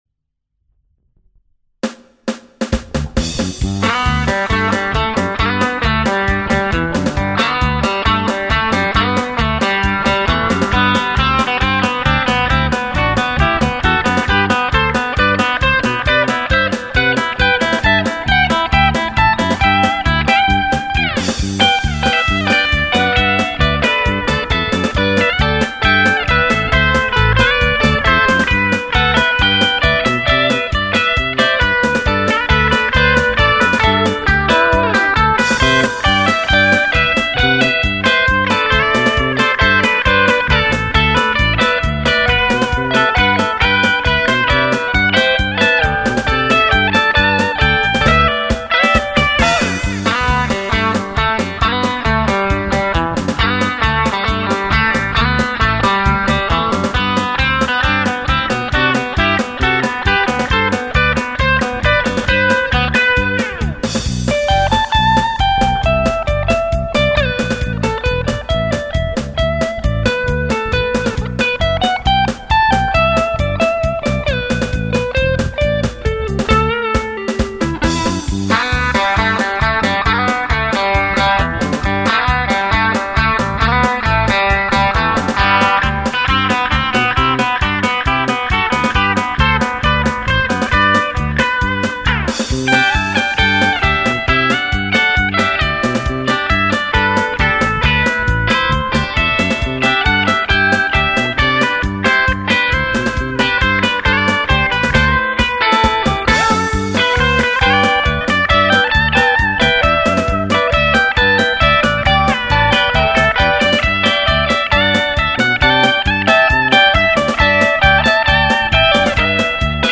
Also heute nochmal, diesmal komplett in Dur. Eingespielt mit meiner Pink Paisley Tele und dem Pod.